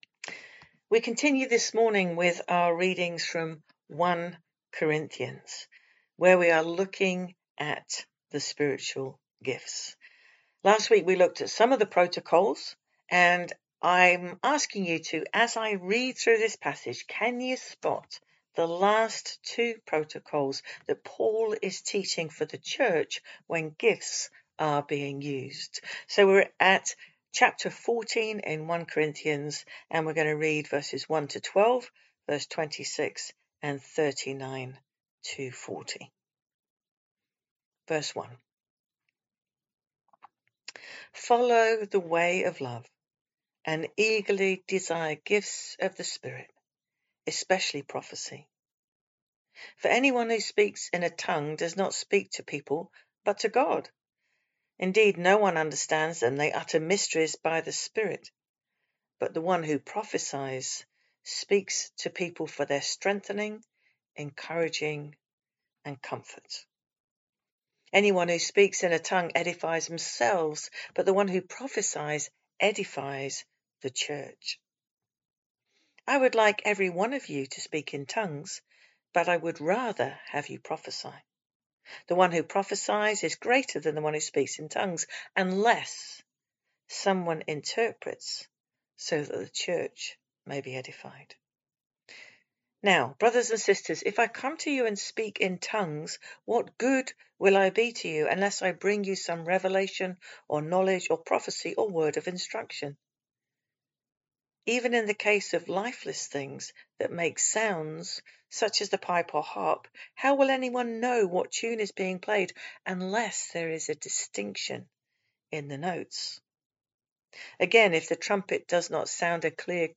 Communication Gifts Of The Spirit | Pulborough Brooks Baptist Church